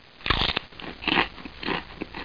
咀嚼-人类声音-图秀网
图秀网咀嚼频道，提供咀嚼音频素材。